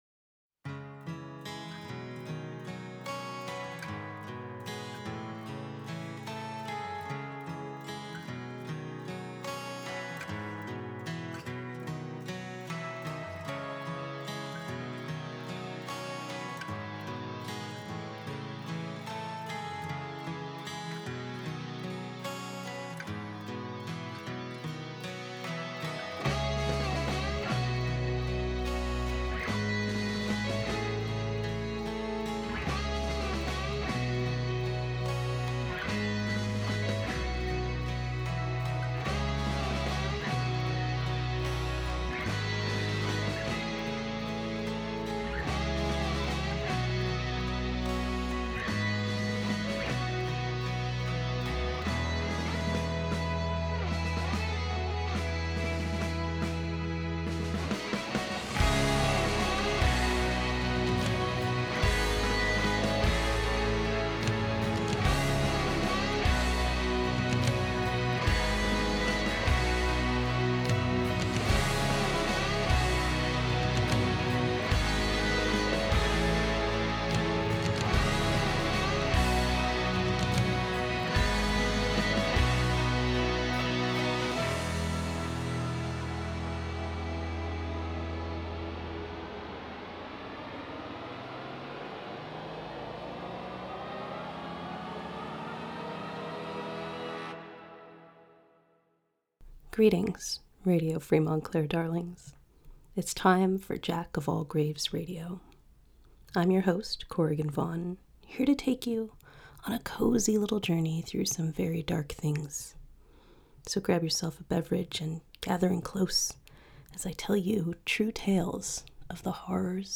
It's a series of stripped down JoAG tales with no crosstalk.